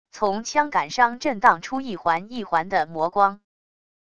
从枪杆伤震荡出一环一环的魔光wav音频